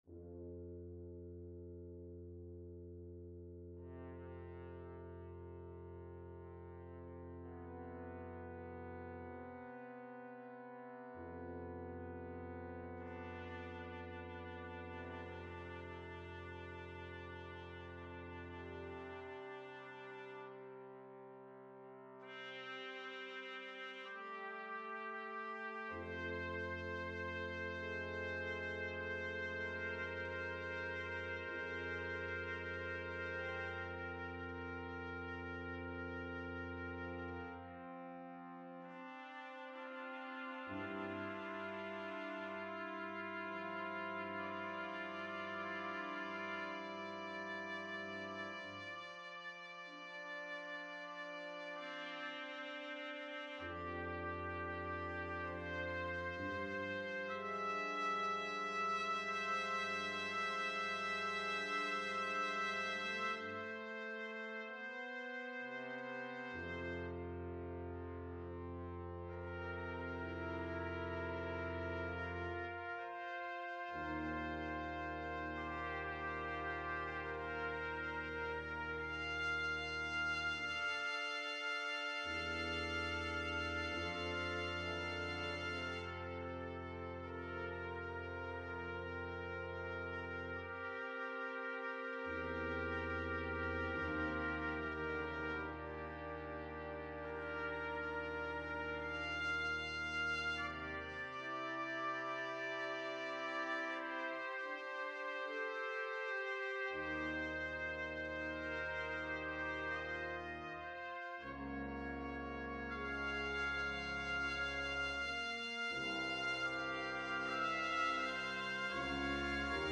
DEMO
Brass Quintet
The music begins with a soundscape that conveys floating in space while the sun slowly starts to reveal itself.
The music slowly becomes warmer with flowing melodies to symbolize the sun warming up and bringing life to the Earth.
The end of the piece returns to the earlier soundscape to symbolize the sun setting as we await its return the next day.